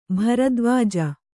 ♪ bharadvāja